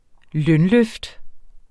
Udtale [ ˈlœn- ]